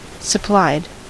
Ääntäminen
Ääntäminen US Tuntematon aksentti: IPA : /sə.ˈplaɪd/ Haettu sana löytyi näillä lähdekielillä: englanti Käännöksiä ei löytynyt valitulle kohdekielelle. Supplied on sanan supply partisiipin perfekti.